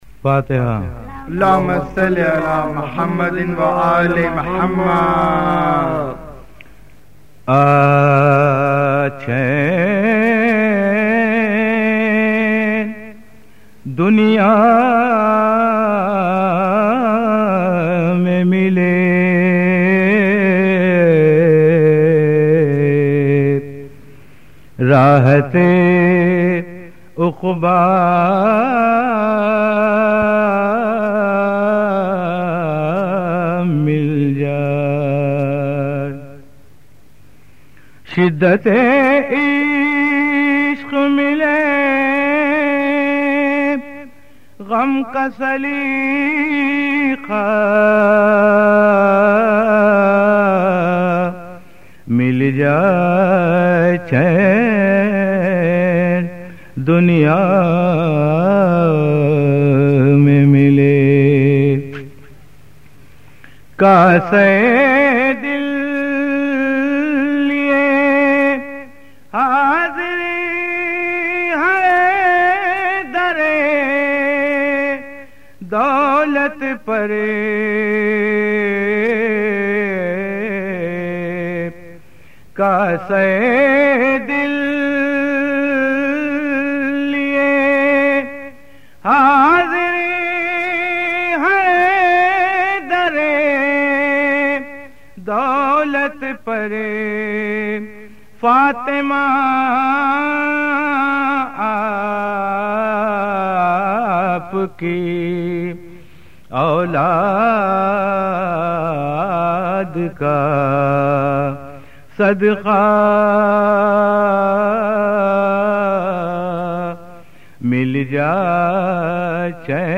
سوزوسلام اورمرثیے